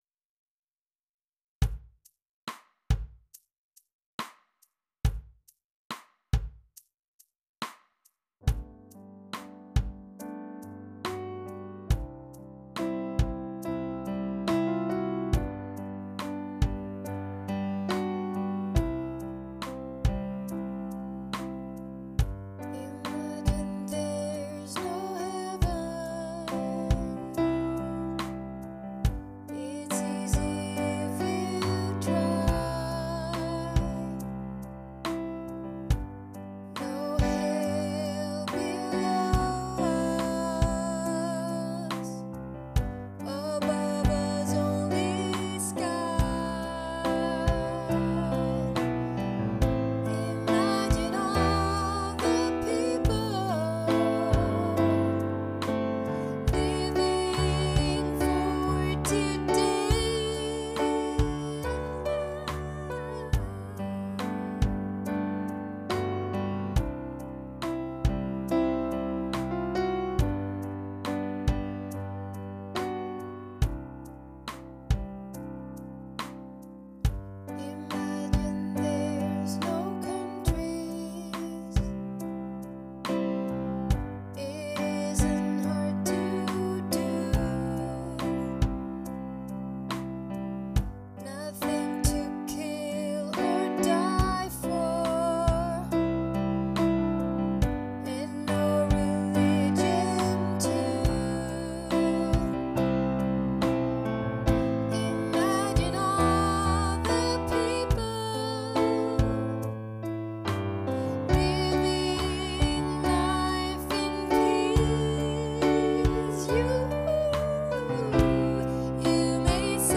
Ljudfil på Imagine i G-dur: